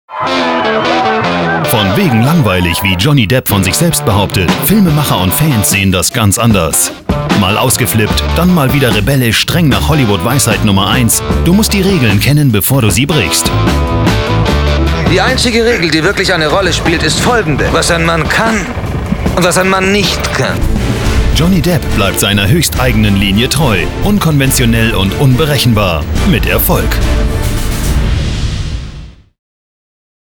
Kein Dialekt
Sprechprobe: Werbung (Muttersprache):
german voice over artist.